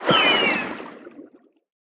Sfx_creature_seamonkeybaby_death_01.ogg